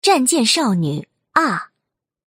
配音 水桥香织